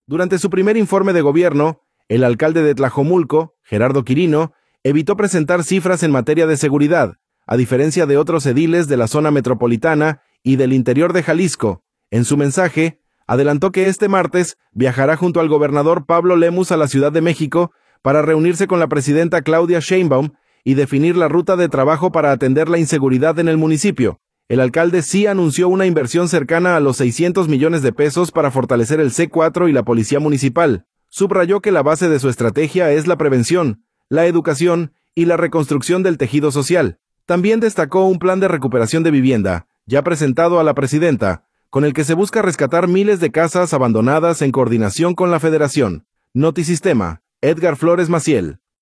audio Durante su primer informe de gobierno, el alcalde de Tlajomulco, Gerardo Quirino, evitó presentar cifras en materia de seguridad, a diferencia de otros ediles de la zona metropolitana y del interior de Jalisco.